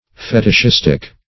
Fetishistic \Fe`tish*is"tic\, a.